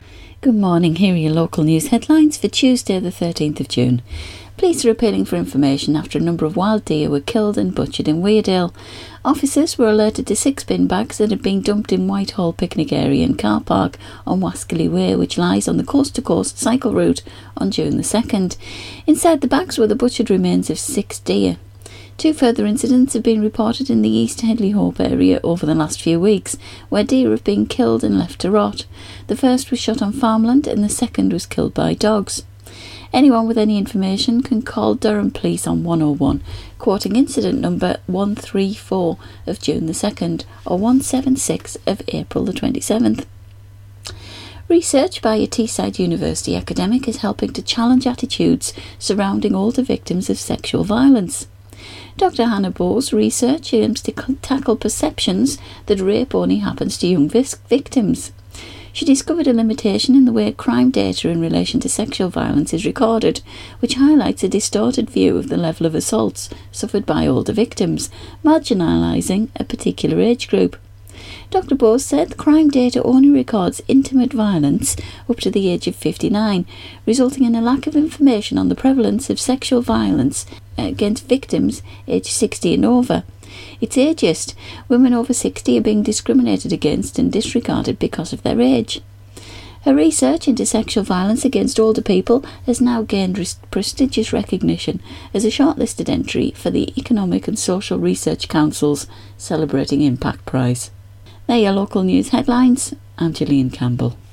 Local News Headlines - Tuesday 13 June 2017